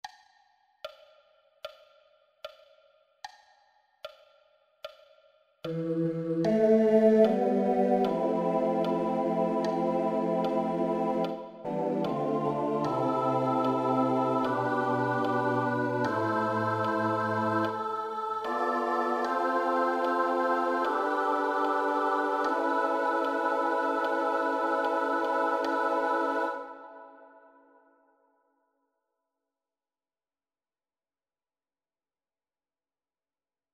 Key written in: A Minor
Type: SATB
All Parts mix: